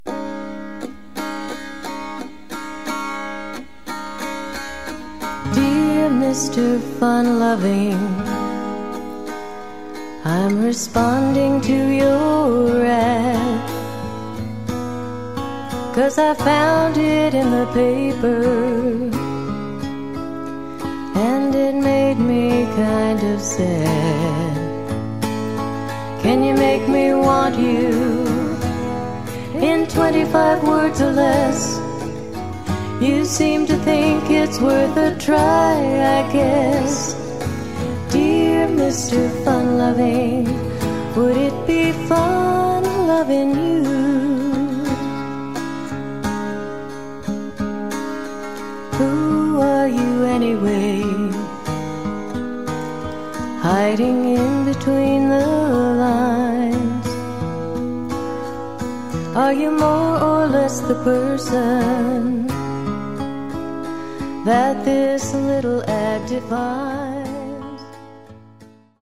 Diving deeper into the dulcimer scene
violin
mandolin